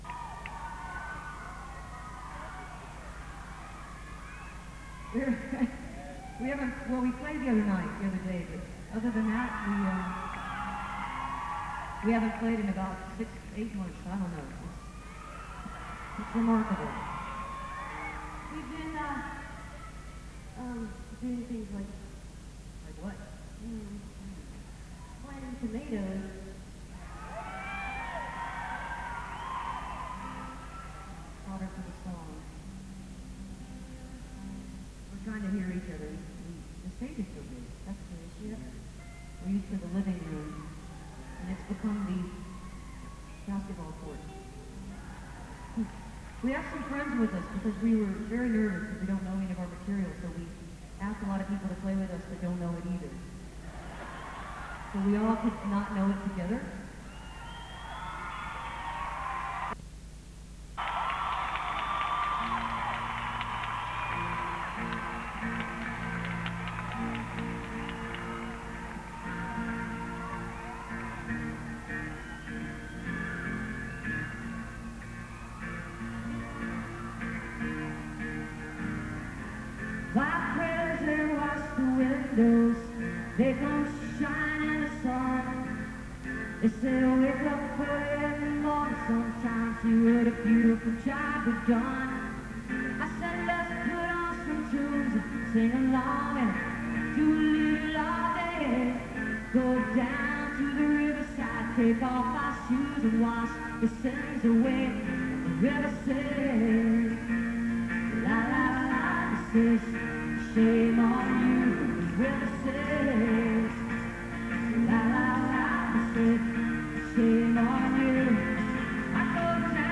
lakefront arena - new orleans, louisiana